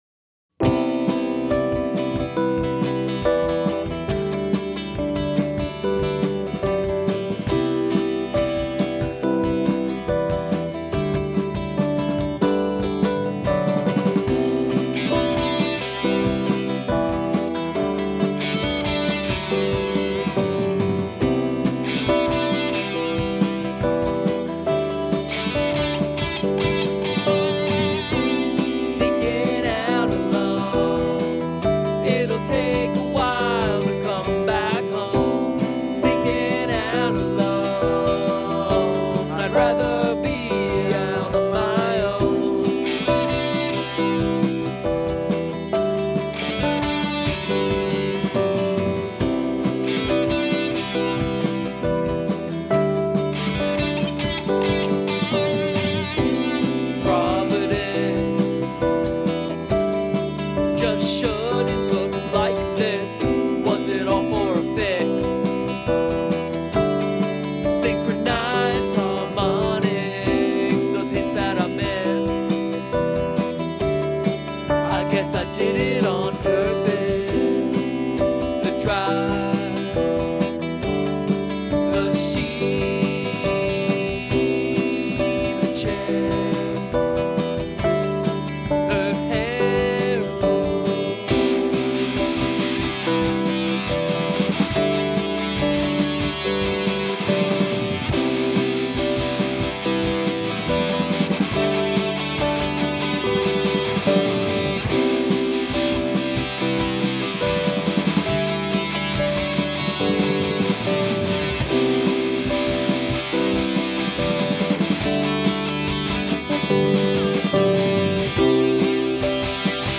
Sneaking Out Alone - LOUD KEYS.wav